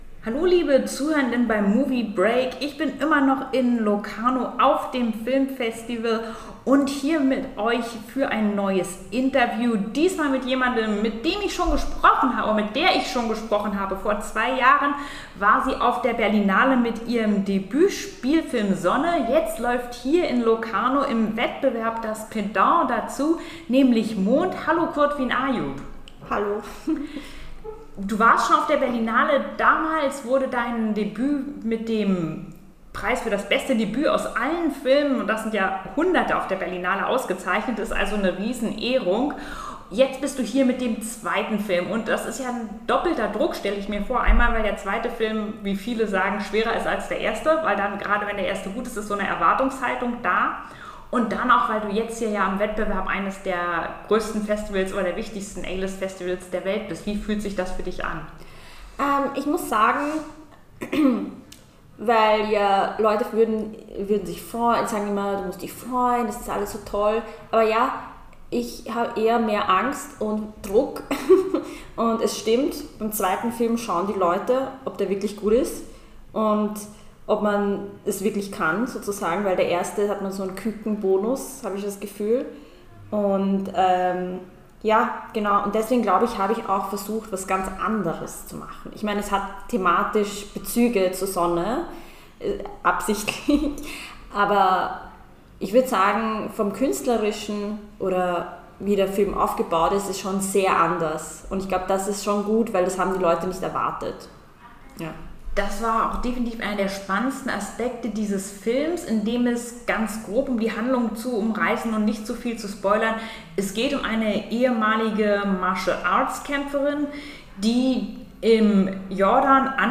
Das mit Elementen von Krimi und Gothic Novel spielende Psychodrama um eine Martial Arts Traineri, die in Jordanien die Töchter einer reichen Familie trainieren soll, könnt ihr bald auch bei uns in den Kinos sehen. Viel Spaß beim Interview!